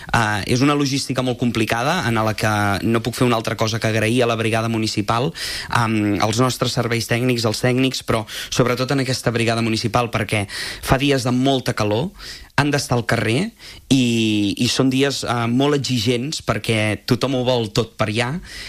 Ho explicava d’aquesta manera al programa a l’FM+ de Ràdio Calella Televisió: